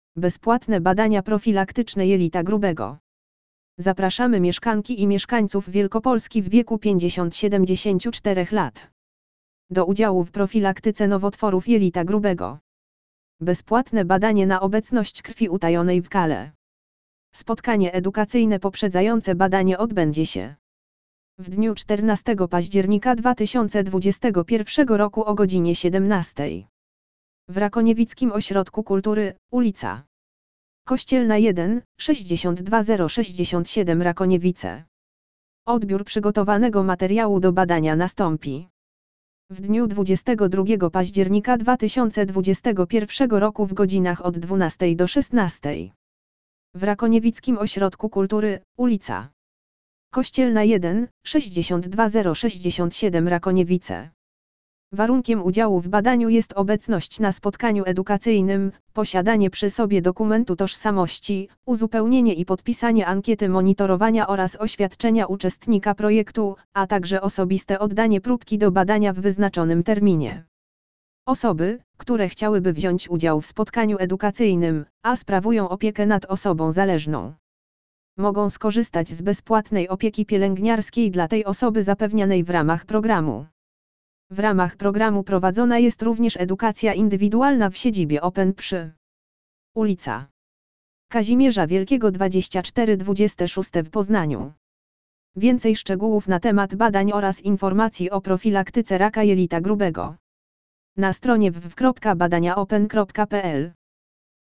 AUDIO LEKTOR BEZPŁATNE BADANIA PROFILAKTYCZNE JELITA GRUBEGO
audio_lektor_bezplatne_badania_profilaktyczne_jelita_grubego.mp3